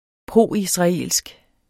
Udtale [ ˈpʁoˀ- ]